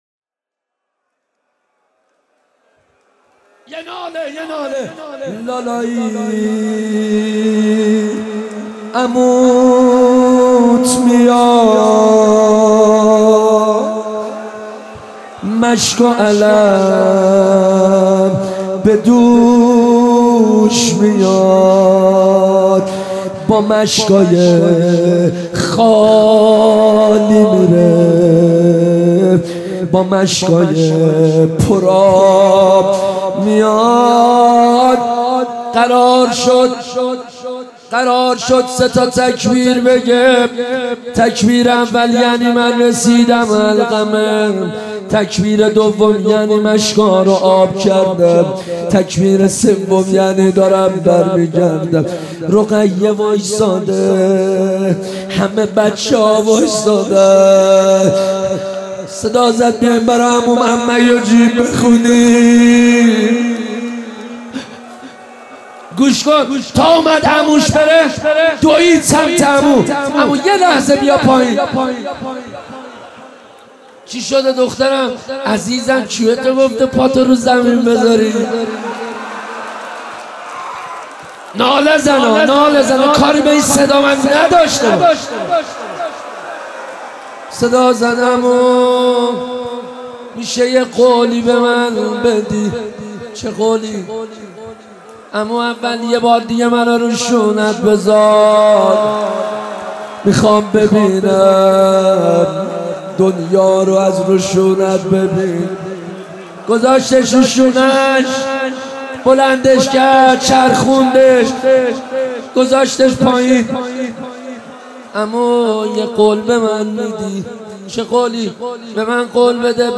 روضه حضرت ابالفضل